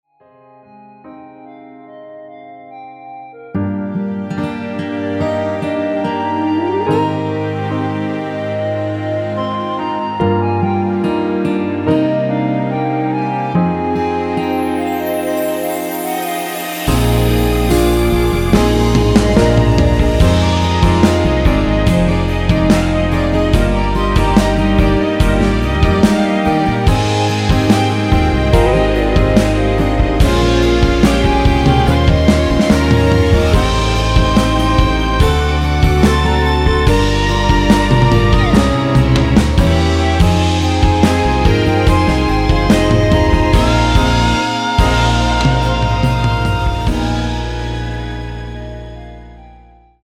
여자키에서(-2)내린 1절후 후렴으로 진행되게 편곡한 멜로디 포함된 MR 입니다.(미리듣기및 가사 참조)
노래가 바로 시작 하는 곡이라 전주 만들어 놓았으며
6초쯤에 노래 시작 됩니다.(멜로디 MR 참조)
Eb
앞부분30초, 뒷부분30초씩 편집해서 올려 드리고 있습니다.
중간에 음이 끈어지고 다시 나오는 이유는